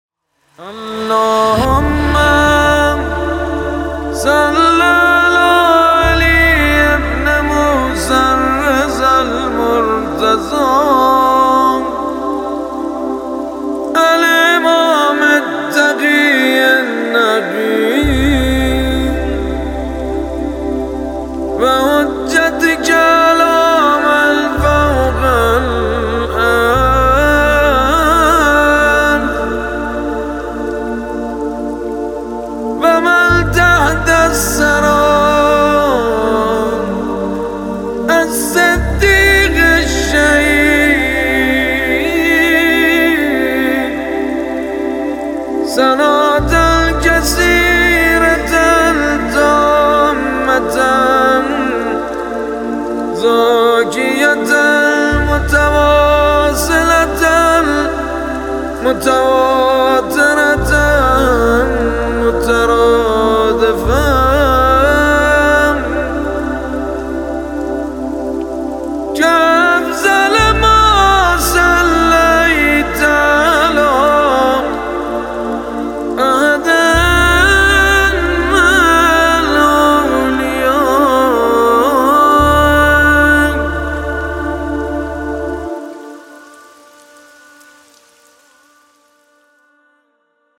فایل صوتی صلوات خاصه امام رضا(ع) با نوای سیدمجید بنی‌فاطمه منتشر شد.
در ادامه فایل صوتی صلوات خاصه امام رضا(ع) را با نوای سیدمجید بنی‌فاطمه مداح اهل‌بیت(ع) می‌شنوید.